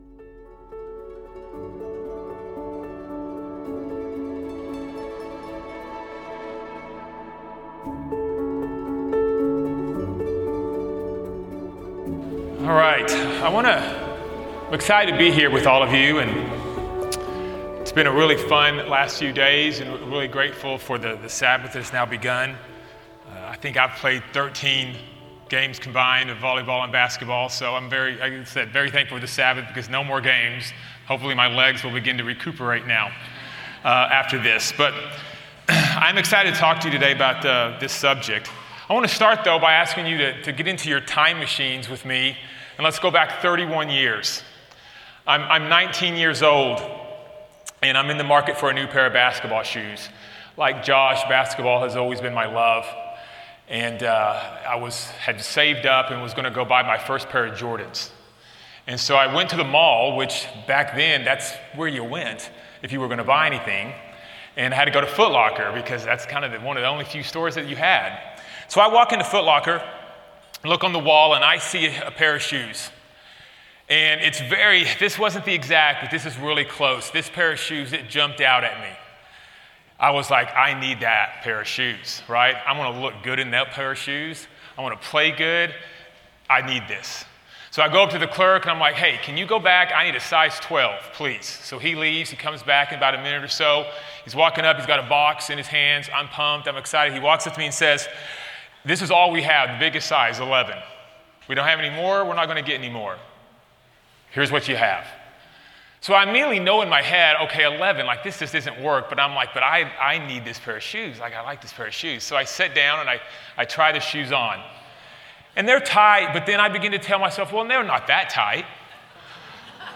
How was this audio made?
This seminar was given during the 2024 Winter Family Weekend.